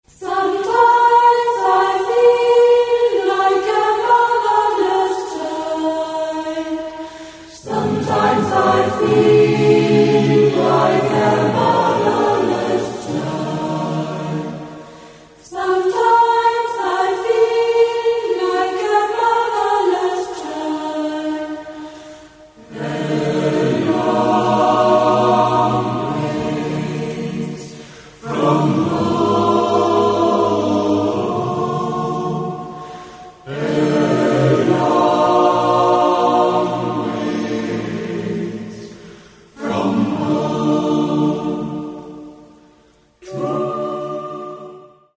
Demo-Aufnahmen - größtenteils Live-Mitschnitte.